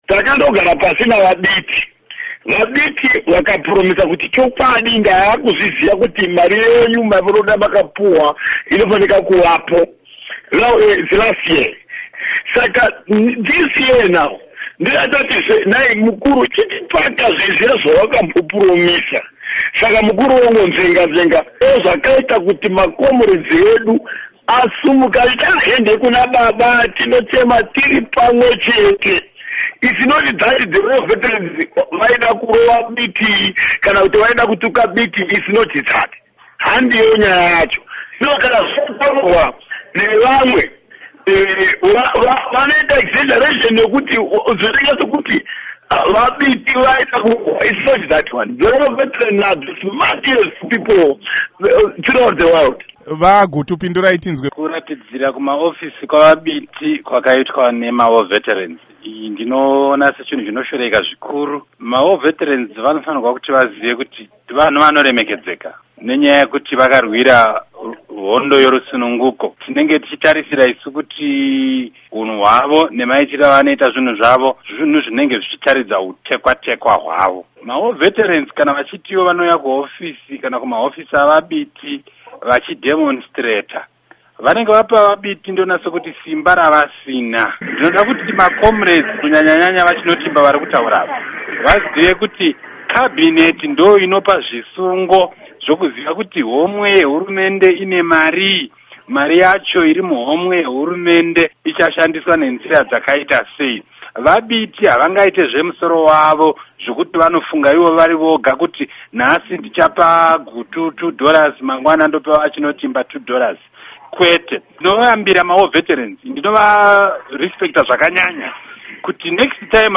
Hurukuro naVaJoseph Chinotimba naVaObert Gutu